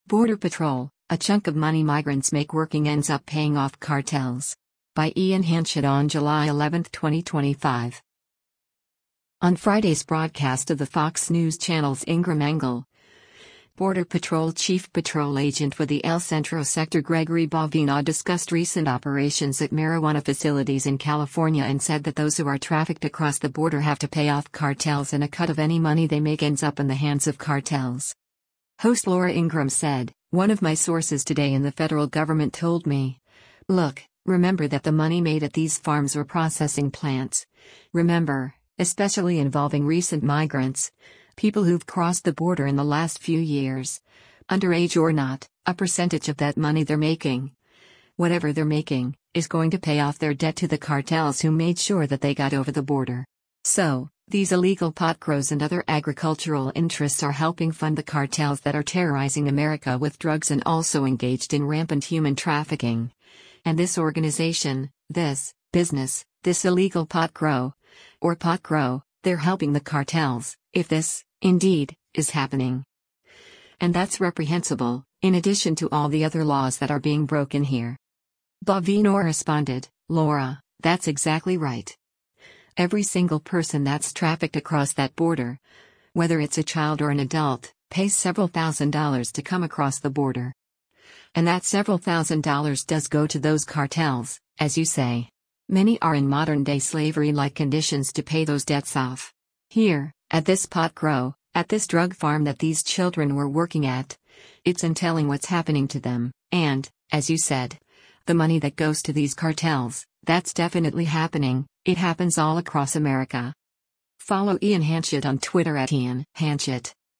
On Friday’s broadcast of the Fox News Channel’s “Ingraham Angle,” Border Patrol Chief Patrol Agent for the El Centro Sector Gregory Bovino discussed recent operations at marijuana facilities in California and said that those who are trafficked across the border have to pay off cartels and a cut of any money they make ends up in the hands of cartels.